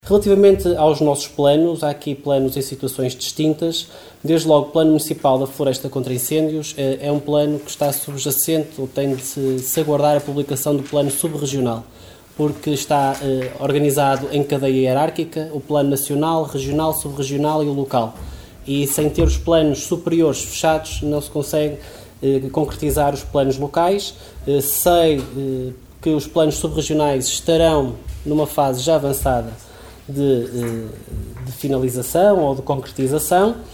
Em resposta à intervenção da vereadora e começando pelo plano de municipal da floresta contra incêndios, o presidente da Câmara explicou que se trata de um plano que está dependente da aprovação do plano sub-regional.
reuniao-camara-6-mar-planos-rui-lages-001.mp3